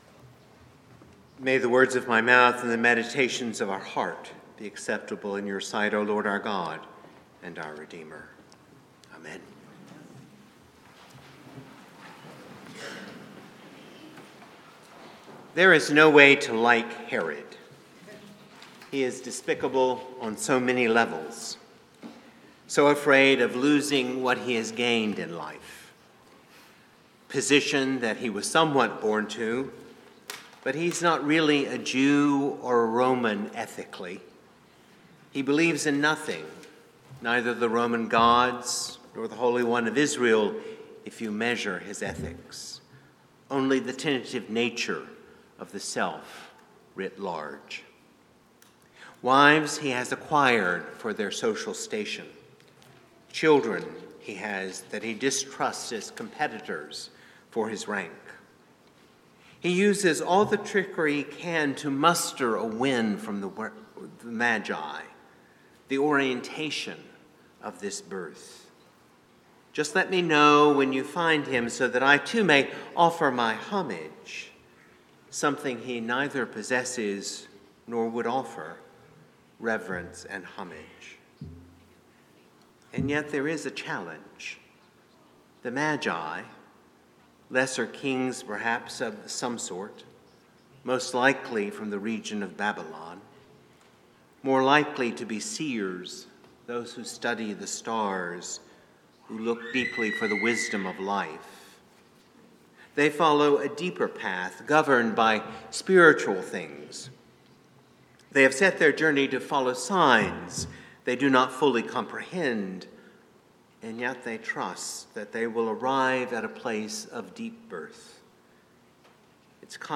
St-Pauls-HEII-9a-Homily-06JAN25.mp3